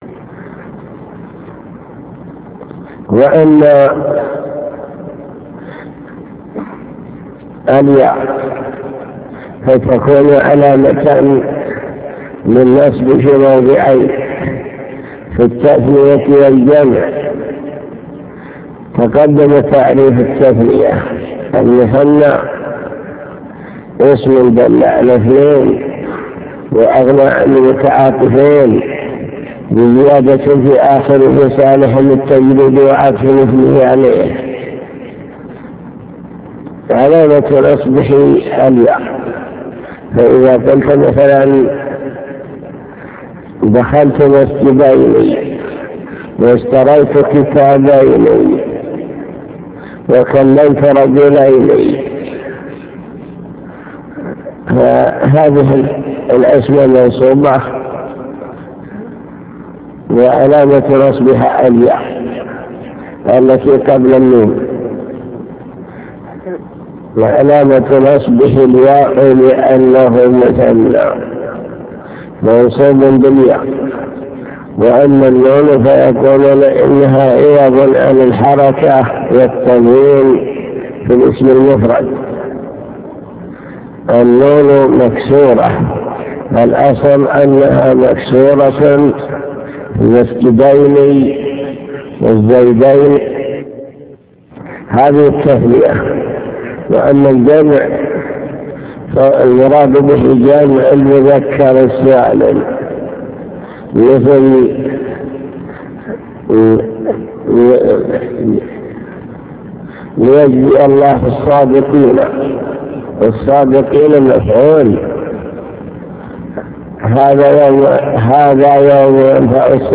المكتبة الصوتية  تسجيلات - كتب  شرح كتاب الآجرومية باب الإعراب أقسام الإعراب النصب وعلاماته